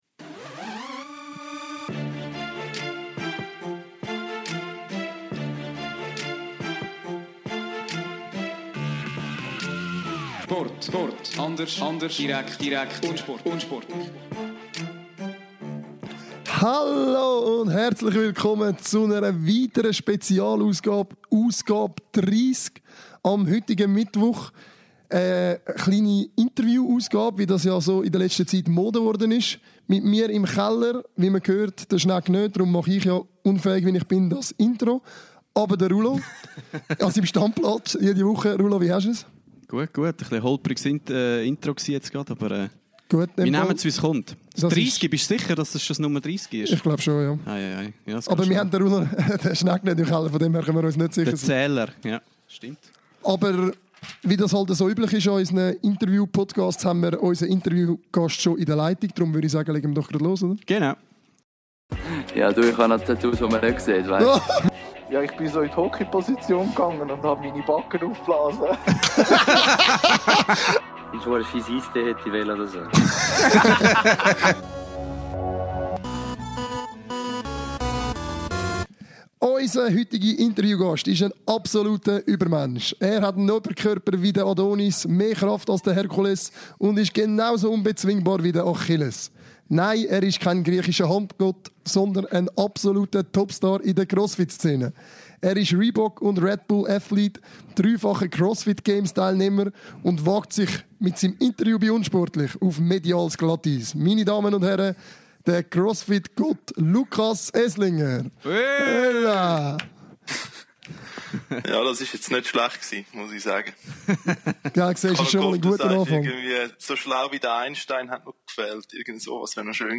Im Interview decken wir die ganze Palette ab.